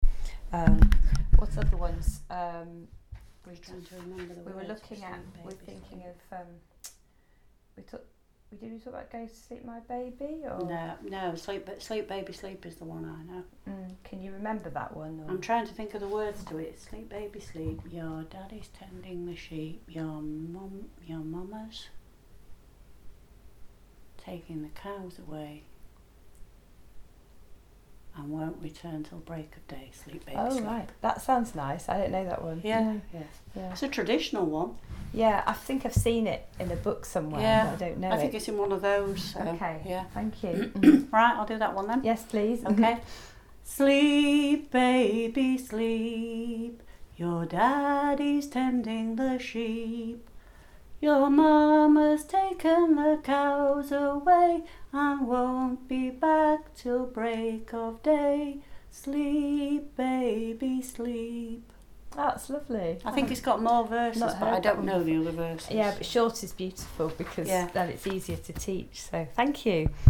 a sung lullaby